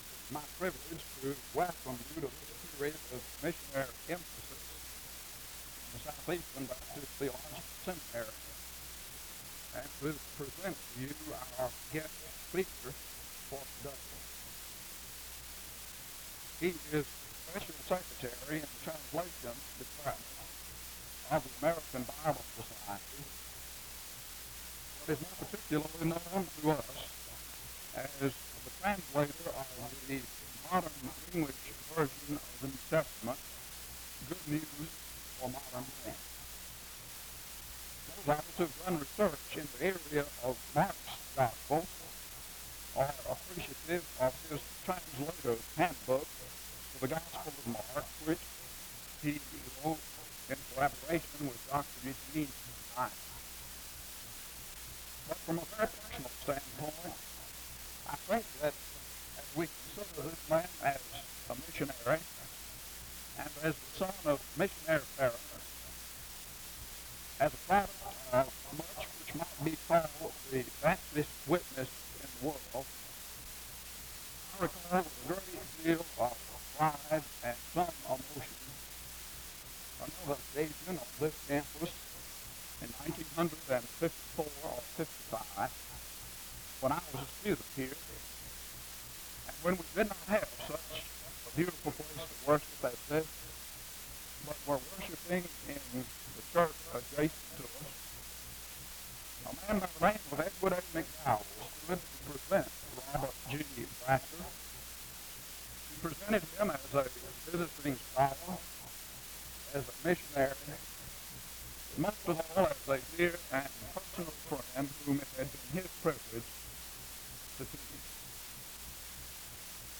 The service begins with an introduction to the speaker from 0:00-2:55.
In Collection: SEBTS Chapel and Special Event Recordings